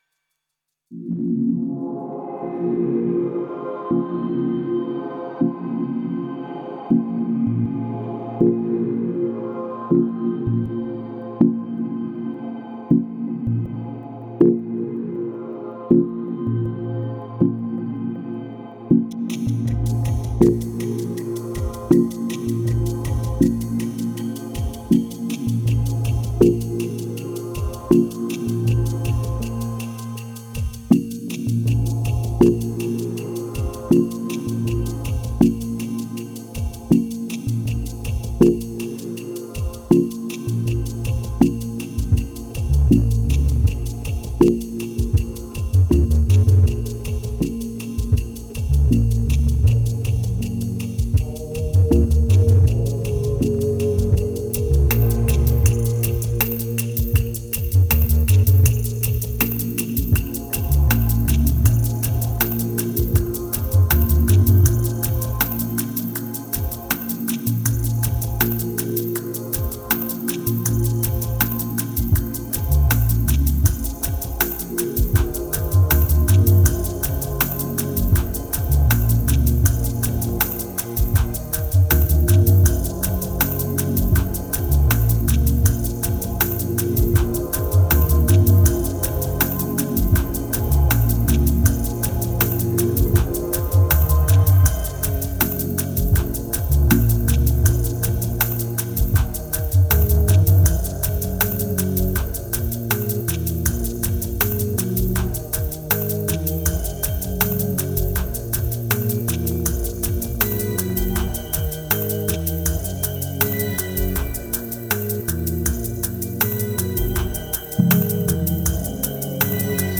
Ambient maybe ?
2320📈 - 92%🤔 - 80BPM🔊 - 2013-08-10📅 - 547🌟